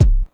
kick01.wav